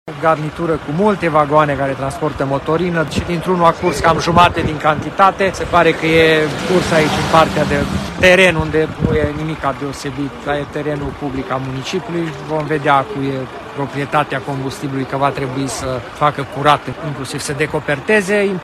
Autorităţile şi compania care operează garnitura afectată trebuie să ia măsuri pentru rezolvarea problemei, spune primarul Călin Bibarț.